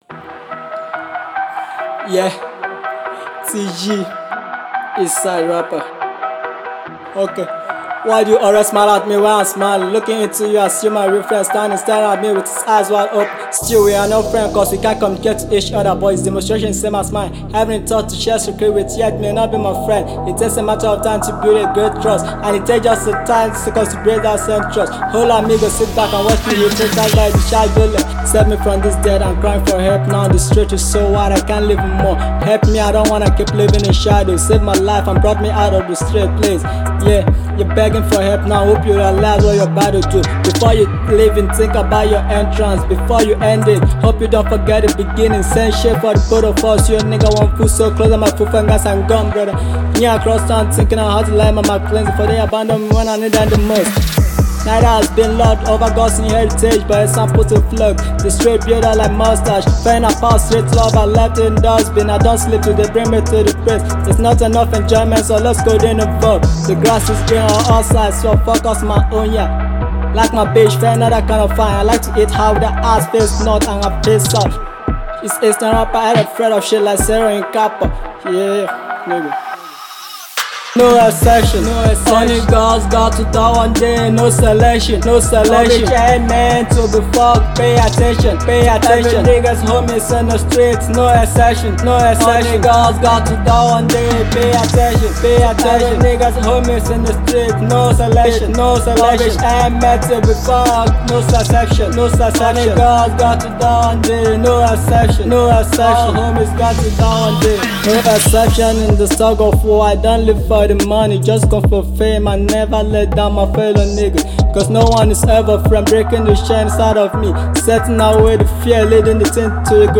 Afro singer